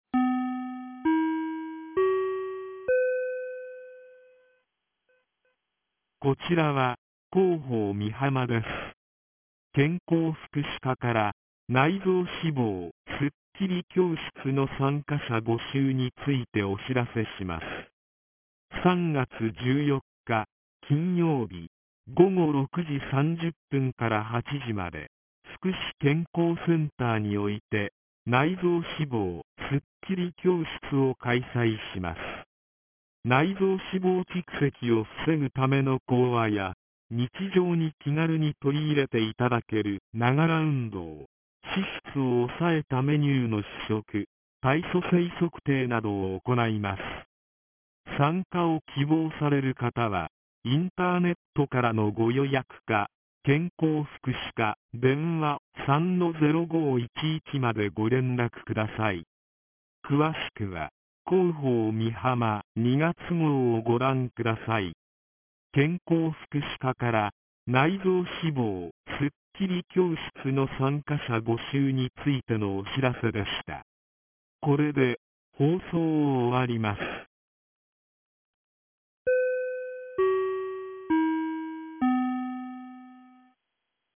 ■防災行政無線情報■